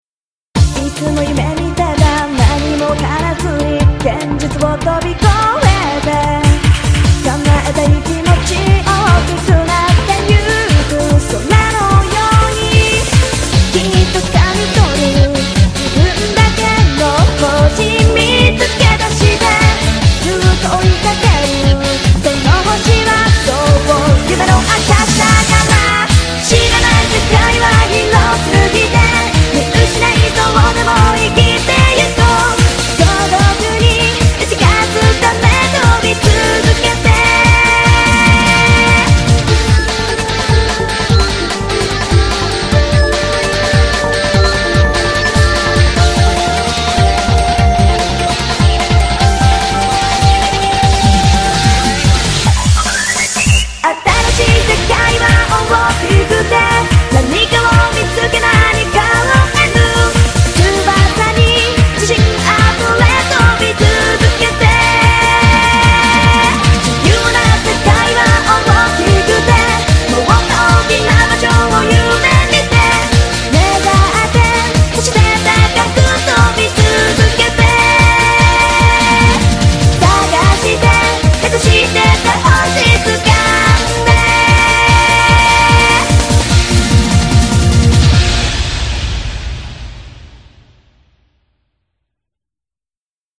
BPM148
Audio QualityPerfect (Low Quality)